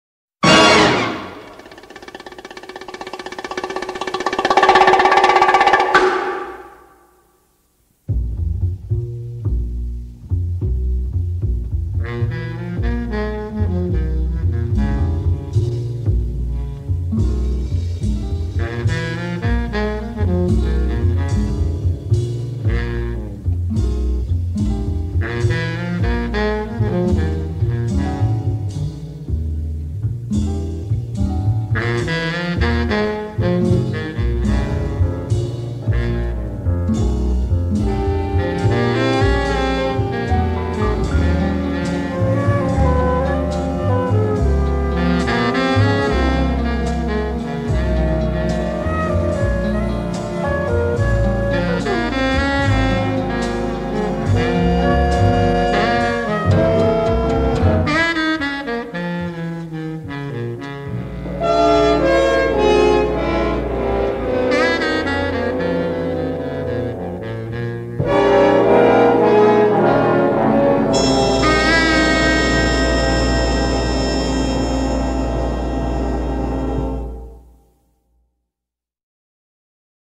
soberbio punteo jazzístico